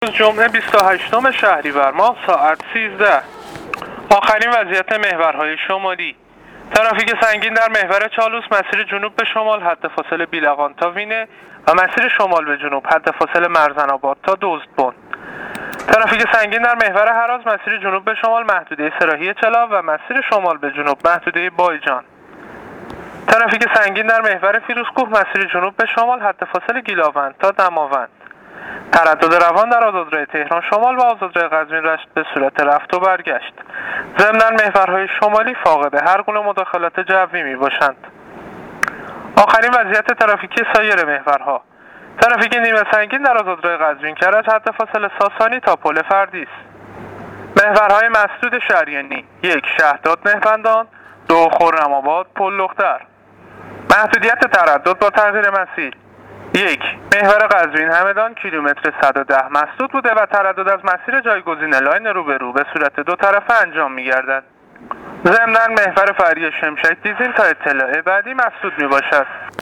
گزارش رادیواینترنتی از وضعیت ترافیکی جاده‌ها تا ساعت ۱۳ جمعه ۲۸ شهريور